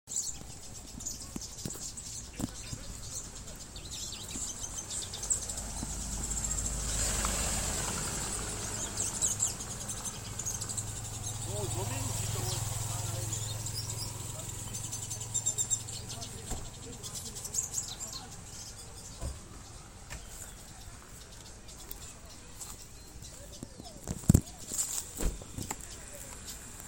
Hooded Siskin (Spinus magellanicus)
Province / Department: Catamarca
Location or protected area: Santa María
Condition: Wild
Certainty: Observed, Recorded vocal